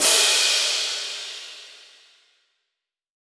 Crash 001.wav